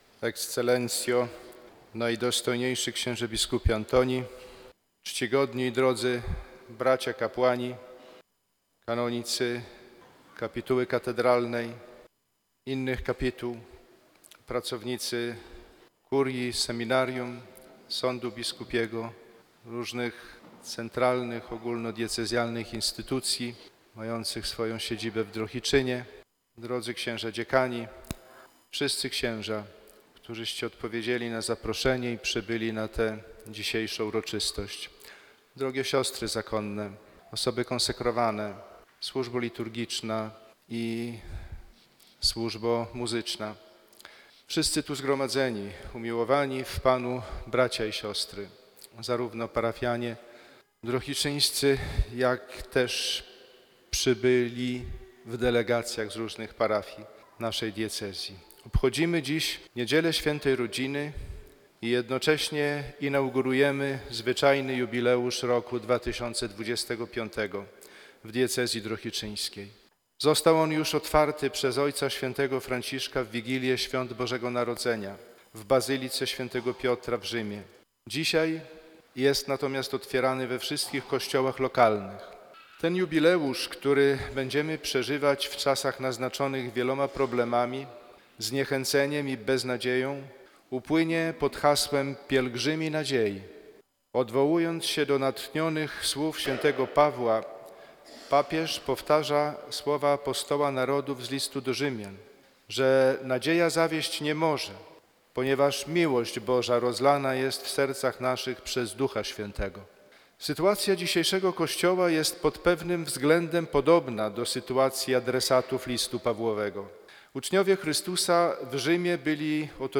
Mamy też rok na konkretny wysiłek, aby być sługami nadziei, by ją umacniać i przywracać w naszych środowiskach” – powiedział bp Piotr Sawczuk w homilii wygłoszonej podczas Eucharystii celebrowanej w drohiczyńskiej katedrze na rozpoczęcie Roku Jubileuszowego 2025.
Posłuchaj homilii bp. Piotra Sawczuka: